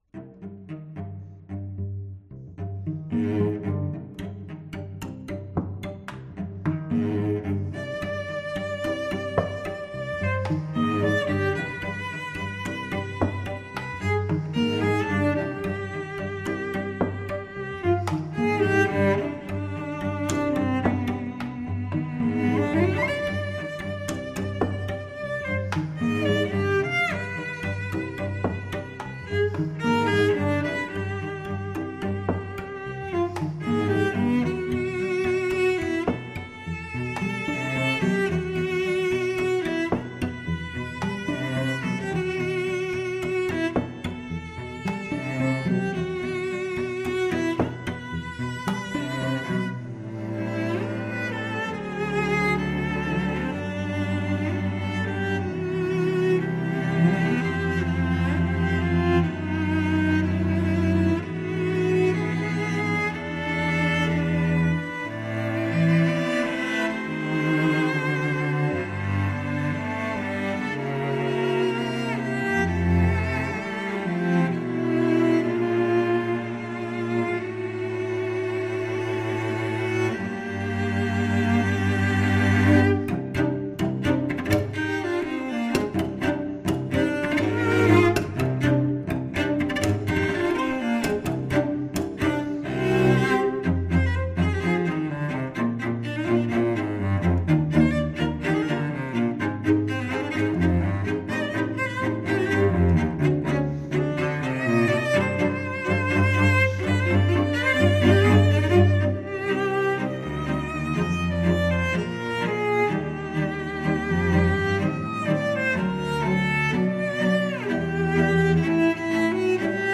Suite per 5 Violoncelli